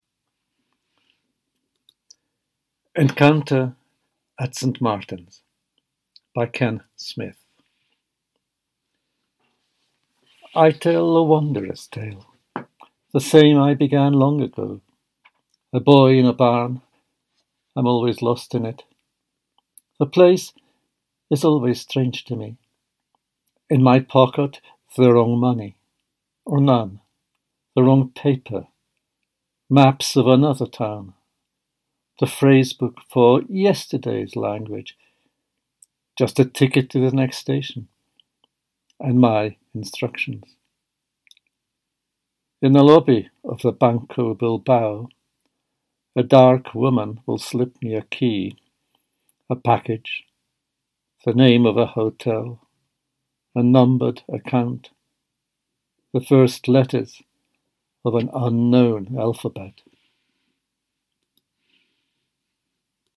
Encounter at St Martin’s by Ken Smith read by George Szirtes
Encounter-at-St-Martins-by-Ken-Smith-read-by-George-Szirtes.mp3